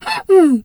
zebra_breath_wheeze_02.wav